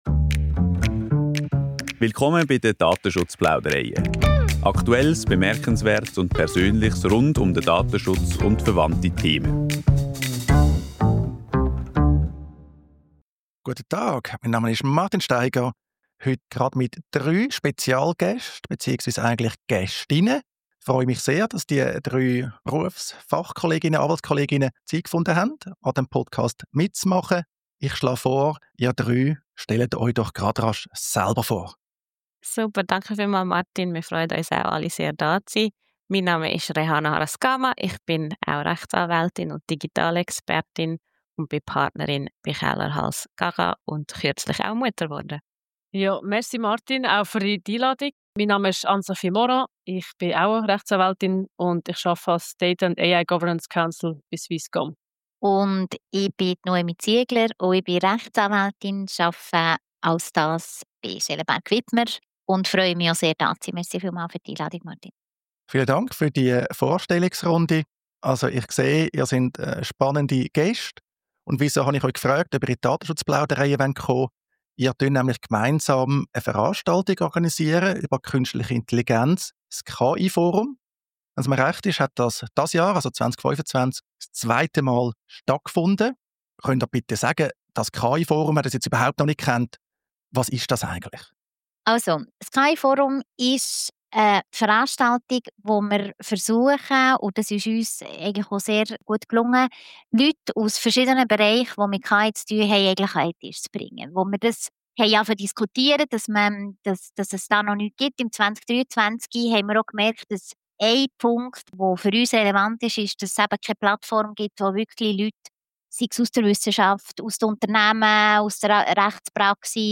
spricht mit den drei Anwaltskolleginnen